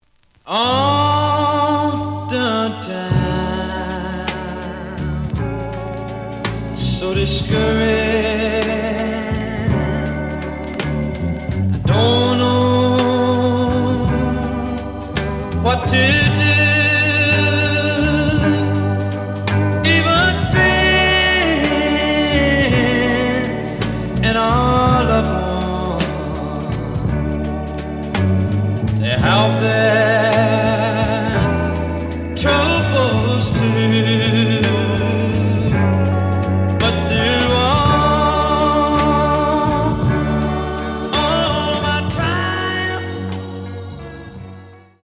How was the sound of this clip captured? The audio sample is recorded from the actual item. Slight damage on both side labels. Plays good.)